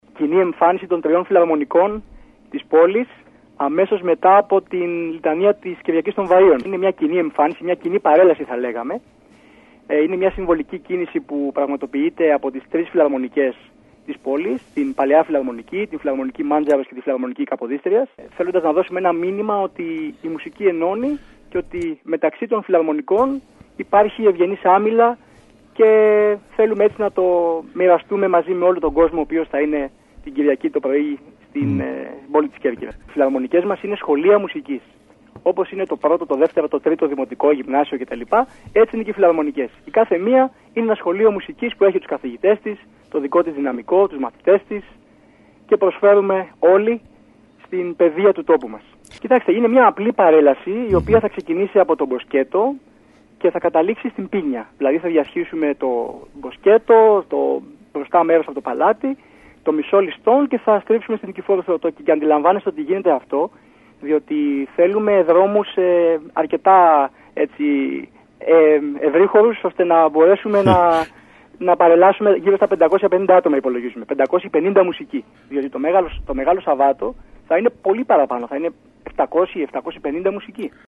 Περιφερειακοί σταθμοί ΚΕΡΚΥΡΑ